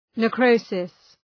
{ne’krəʋsıs}